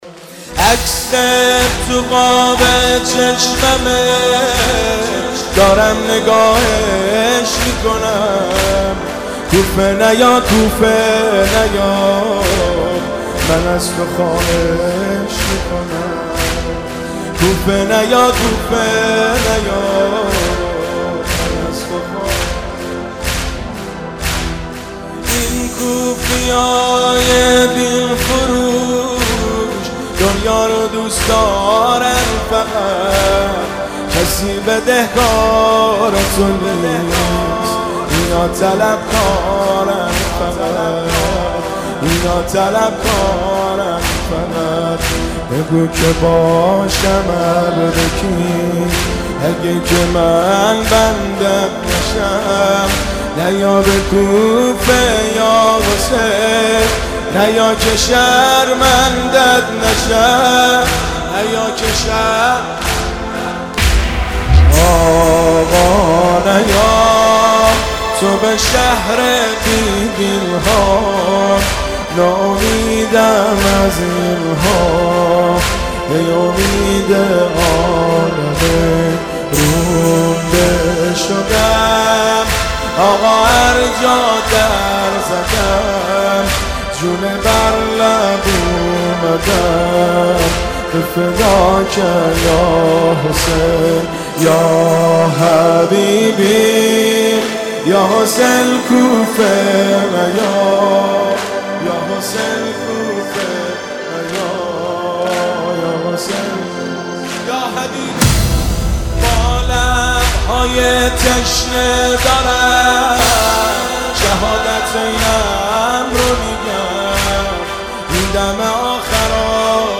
عکست توو قاب چشممه دارم نگاهش میکنم زمینه – شب اول محرم الحرام 1392 هیئت خادم الرضای قم